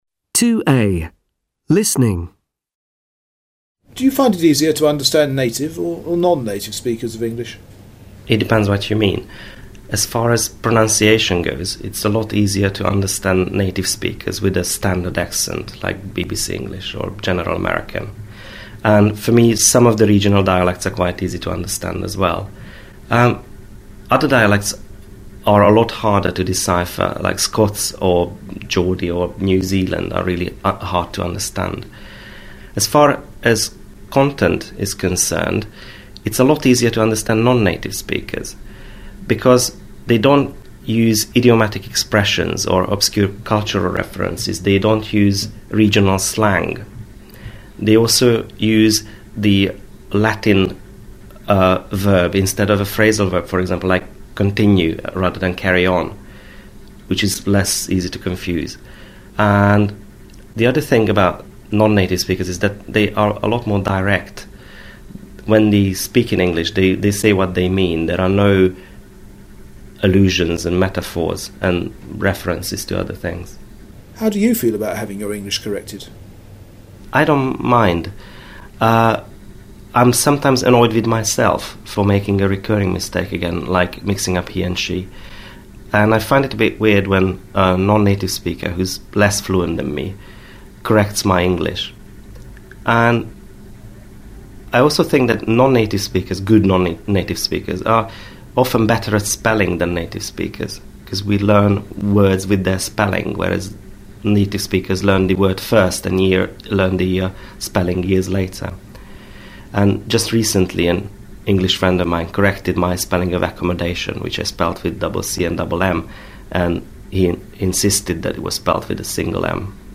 native_nonnative_U1.mp3